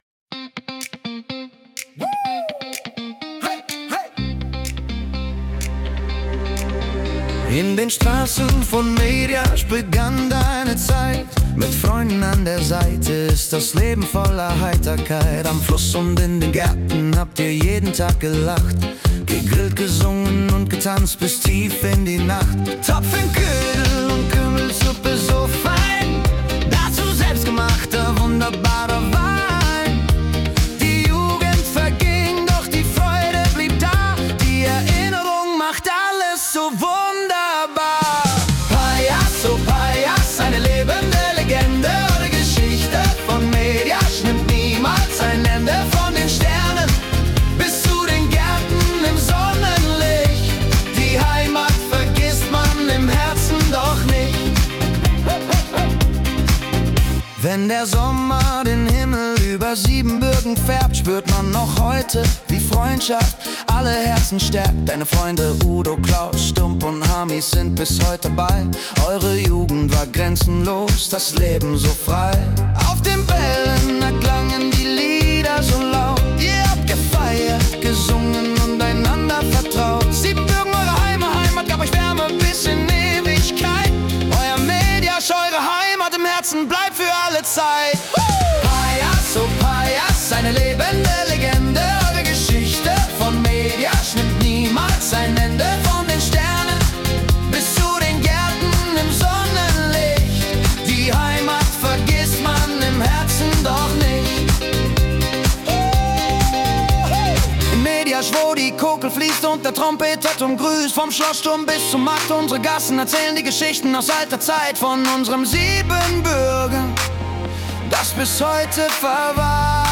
Pop
• KI-generierte Melodie